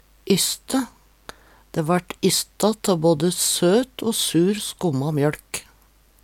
Sjå òg kjælring, kokering (Veggli) løypeløg (Veggli) kjeseløg (Veggli) kokehytte (Veggli) ostekjuv (Veggli) silingsbaLe (Veggli) ysstingsbaLe (Veggli) mjøLkebu (Veggli) Høyr på uttala